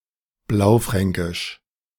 Blaufränkisch (German pronunciation: [ˈblaʊ̯ˌfʁɛŋkɪʃ]
De-Blaufränkisch.ogg.mp3